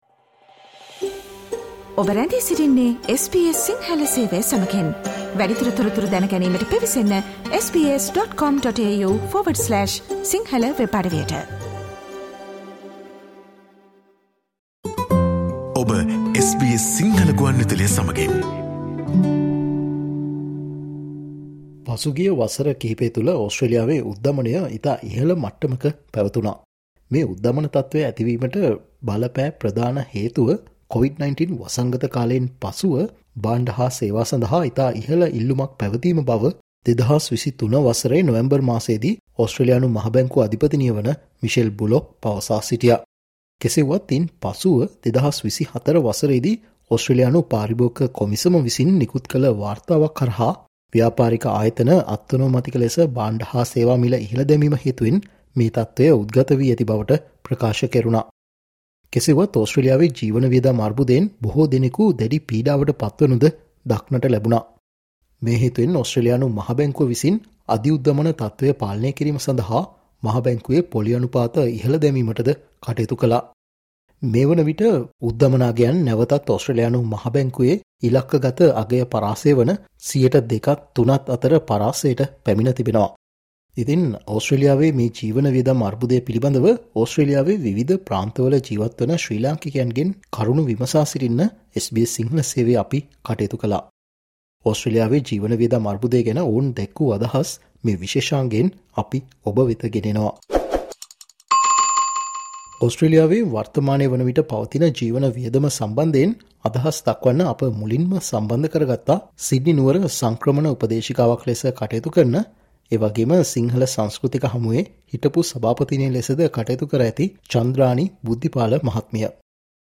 ඕස්ට්‍රේලියාවේ මේ දිනවල ජීවන වියදම ගැන ශ්‍රී ලාංකිකයින් දැක්වූ අදහස් මෙන්න